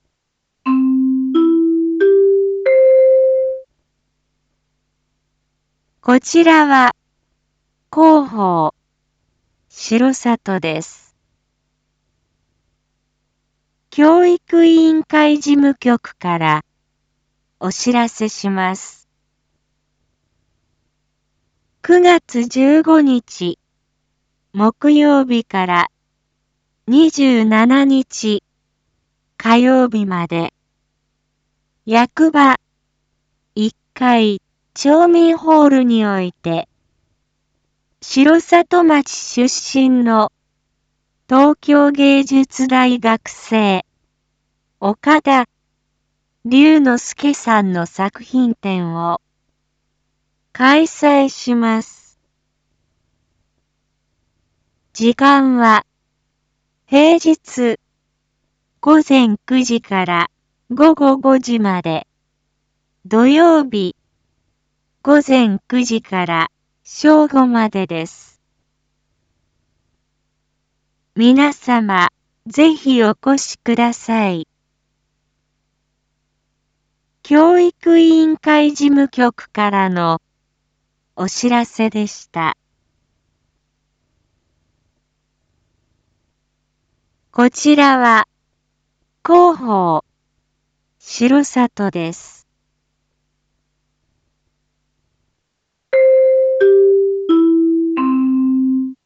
Back Home 一般放送情報 音声放送 再生 一般放送情報 登録日時：2022-09-14 07:01:40 タイトル：R4.9.14 7時放送分 インフォメーション：こちらは広報しろさとです。